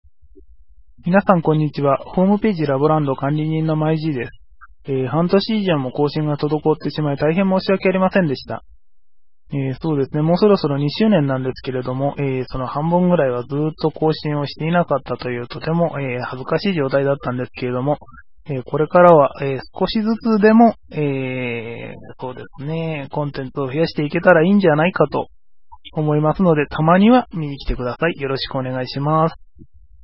声のメッセージ
ファイルは写真２枚分くらいの大きさです．音質はラジオ程度かな？